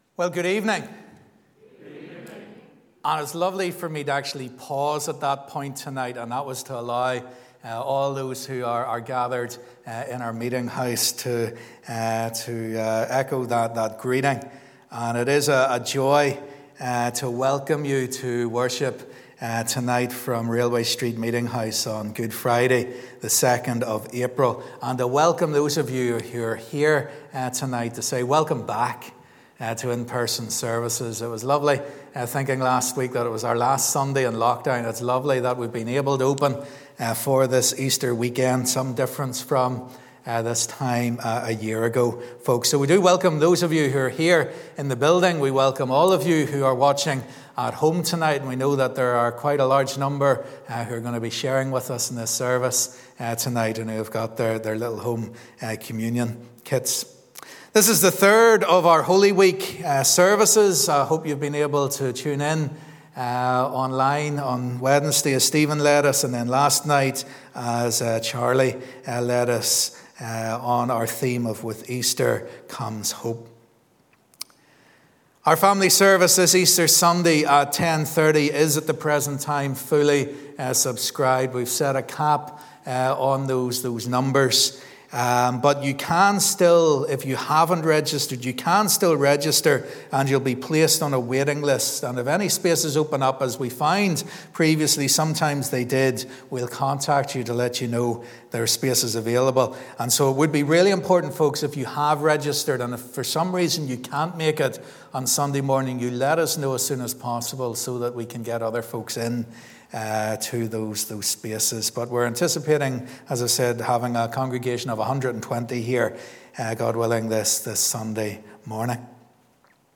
This evening is the third of our Holy Week services based on the theme 'With Easter Comes Hope'. Join us as discover hope in the Cross. We will also be joining together for communion.